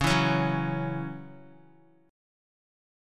C#mbb5 chord